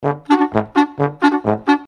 • Качество: 320, Stereo
без слов
труба
Шустрая нарезка на еврейский или молдавский лад